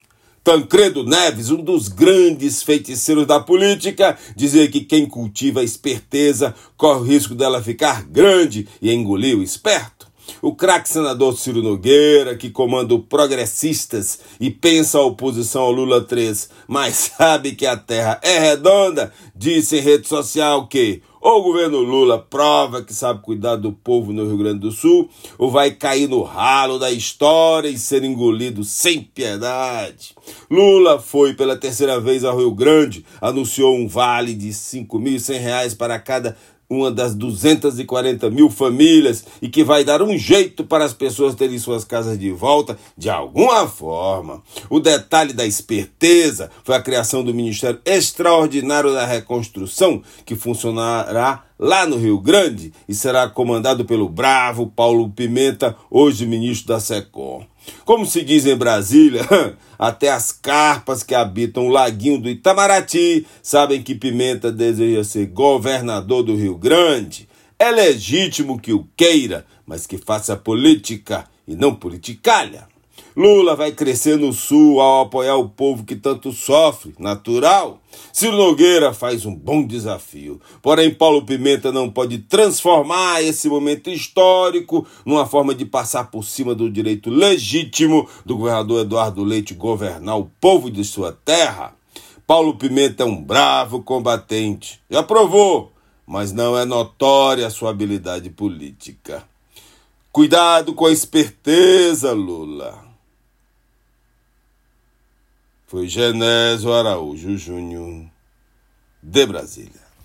Comentário
direto de Brasília